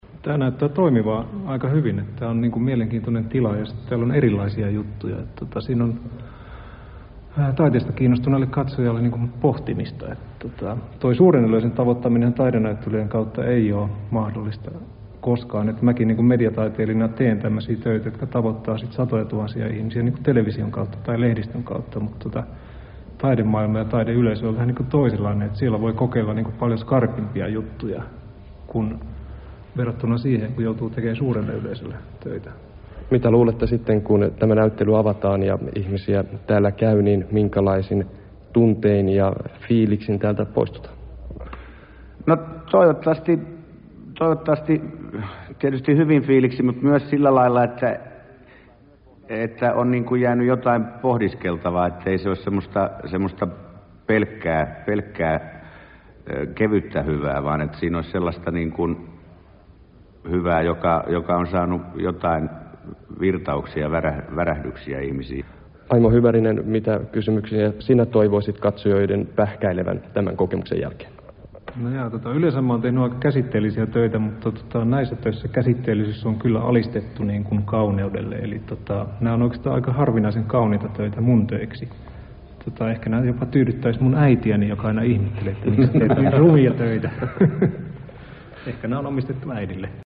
Radiohaastattelu hommasta